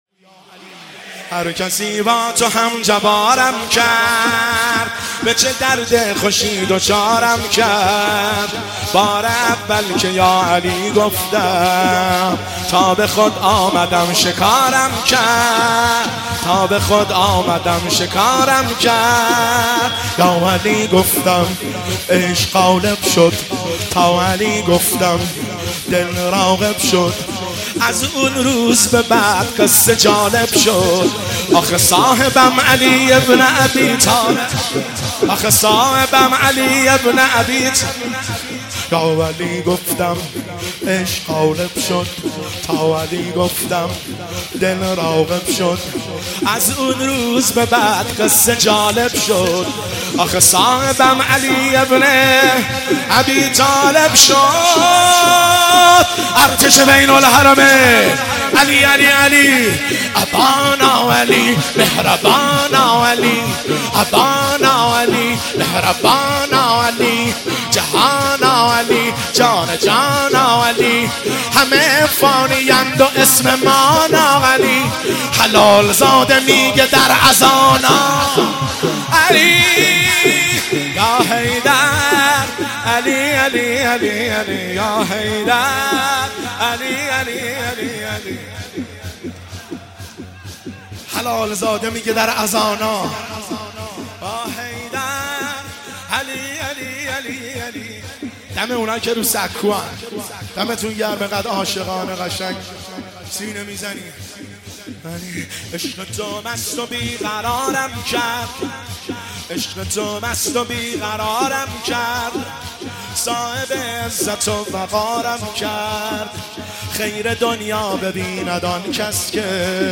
شور شب 23 رمضان المبارک 1403
هیئت بین الحرمین طهران